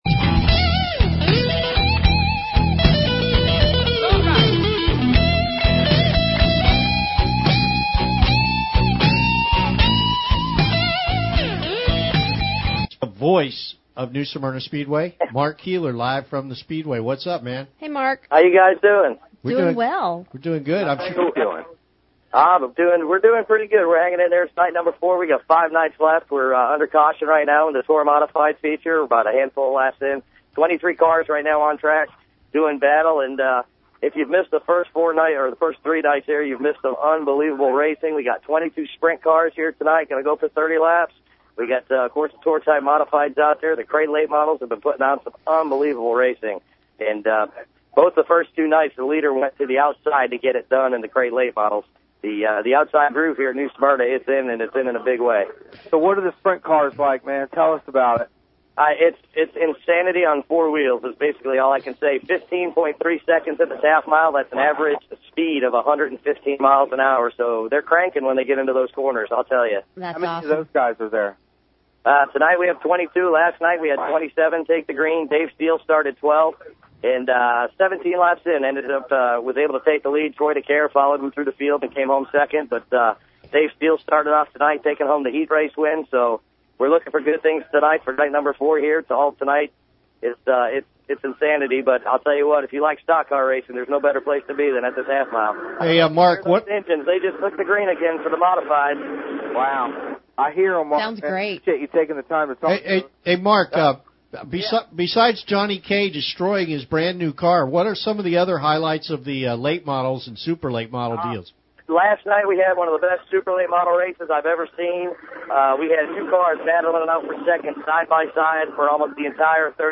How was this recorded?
Live from the tower on Monday evening, the excitement is contagious at the World Series of Asphalt Stock Car Racing.